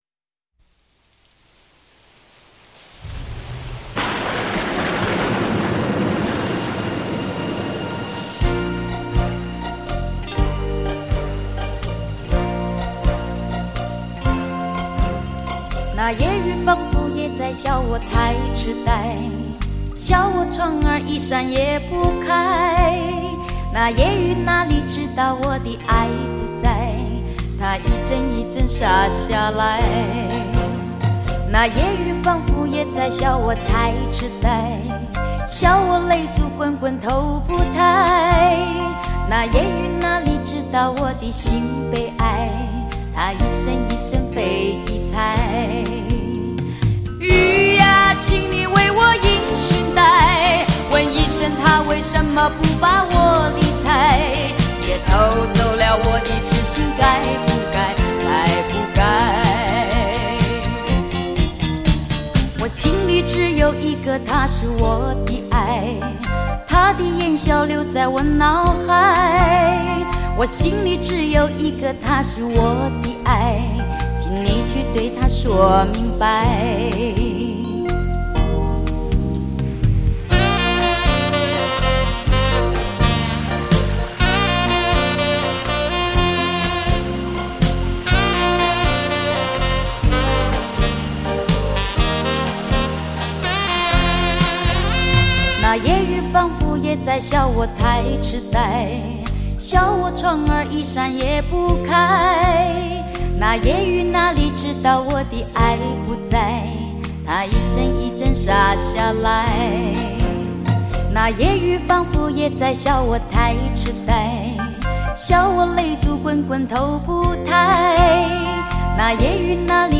这些洋曲重新填上中文歌词演唱，有些还是第一次听到呢！
管弦乐编曲之优美曼妙，更不逊于欧美的轻音乐团